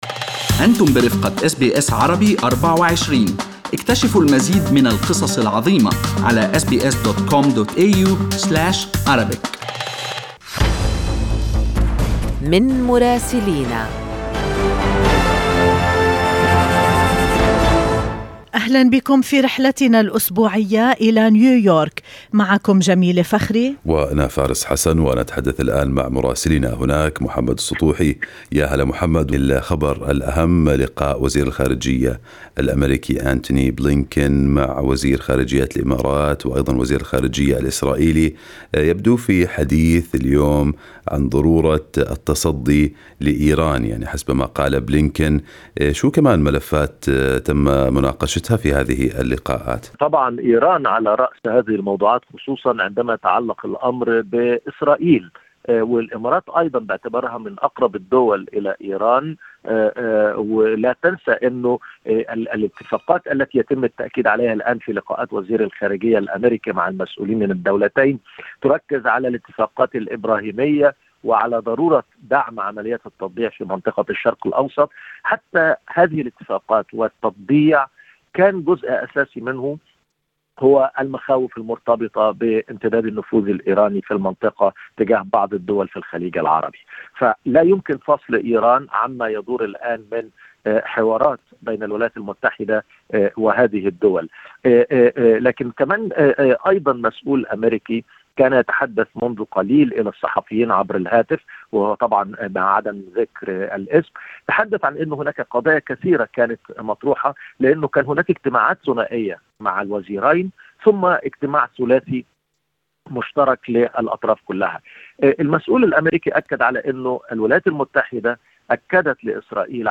يمكنكم الاستماع إلى تقرير مراسلنا في نيويورك بالضغط على التسجيل الصوتي أعلاه.